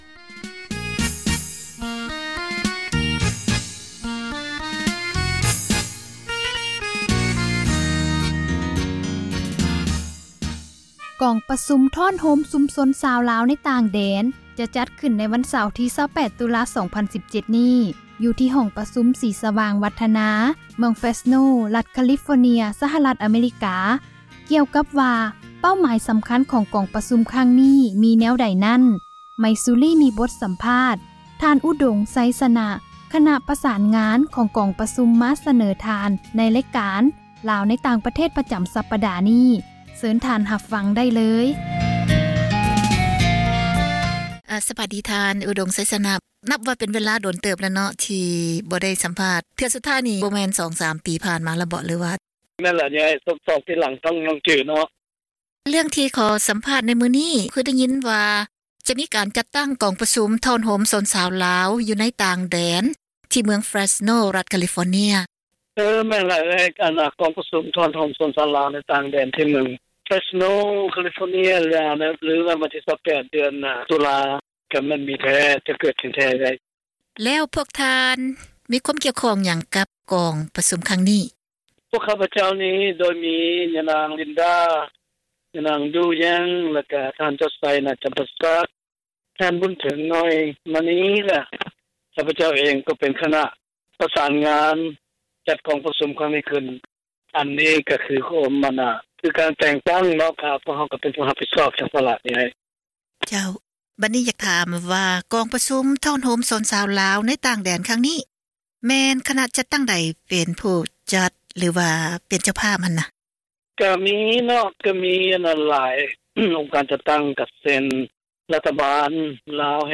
ມີບົດສັມພາດ